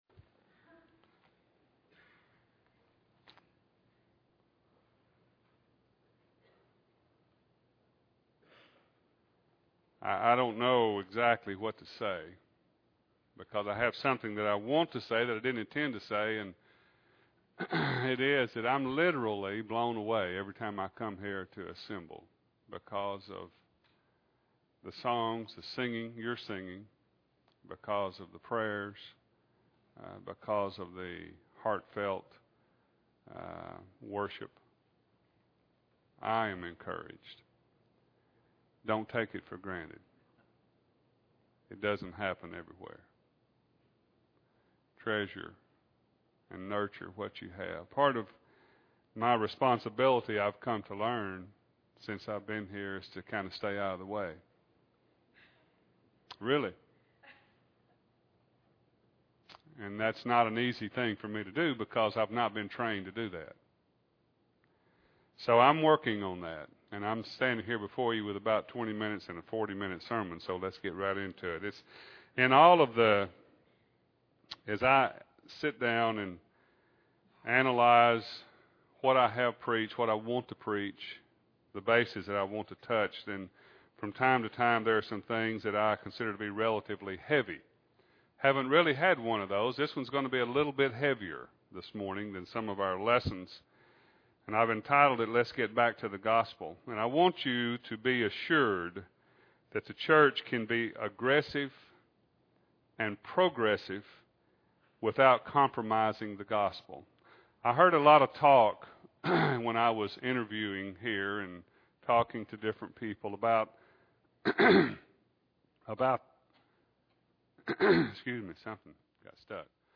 2008-02-03 – Sunday AM Sermon – Bible Lesson Recording